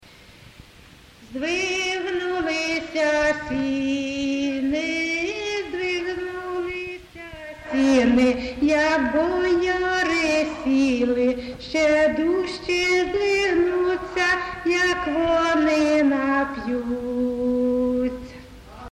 ЖанрВесільні
Місце записус-ще Зоря, Краматорський район, Донецька обл., Україна, Слобожанщина